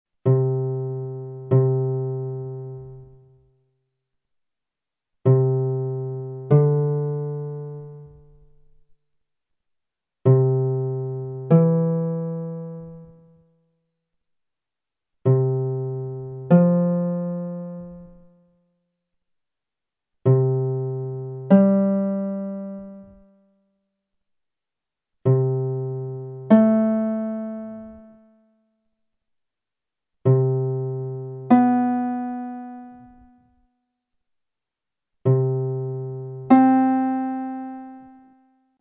Proviamo ora a costruire gli intervalli prendendo, come primo termine, la nota DO della scala di Do Maggiore.
A - unisono giusto
B - seconda maggiore
C - terza maggiore
D - quarta giusta
E - quinta giusta
F - sesta maggiore
G - settima maggiore
H - ottava giusta
Ascoltiamoli in successione:
serie_intervalli_Do.mp3